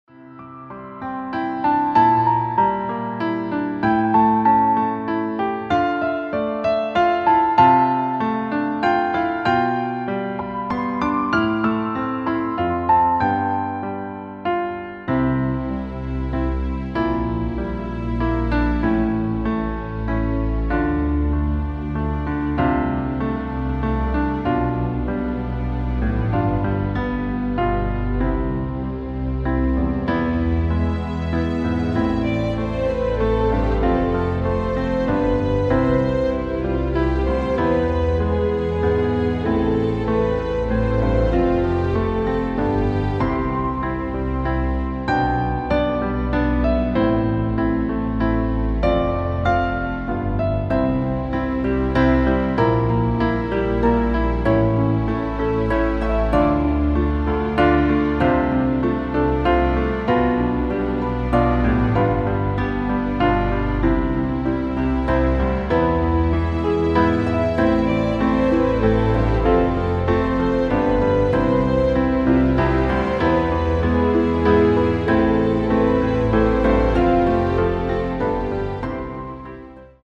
als Klavierversion
C Dur Art: Klavier Streicher Version Demo in voller Länge
Wichtig: Das Instrumental beinhaltet NICHT die Leadstimme
Klavierplayback
Trauermusik deutsch